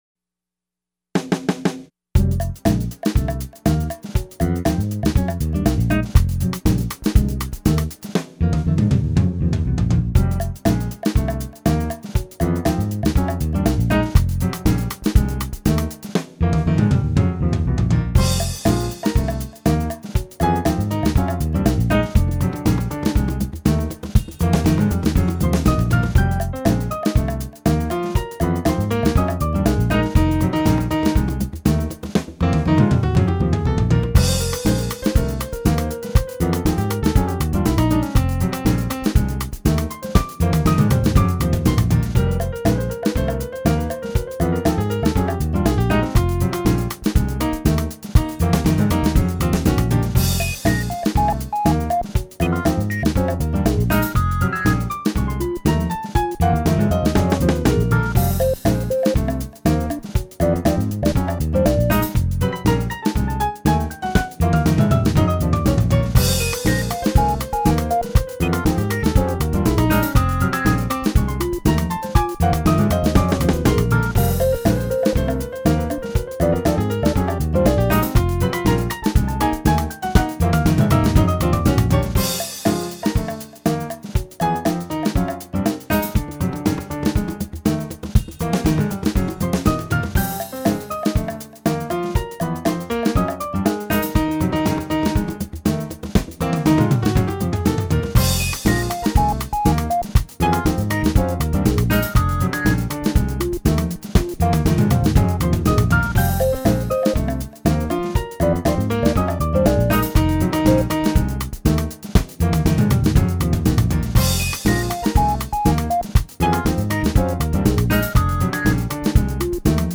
拉丁爵士乐